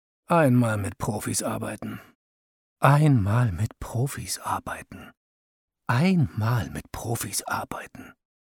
hell, fein, zart, markant, sehr variabel
Commercial (Werbung)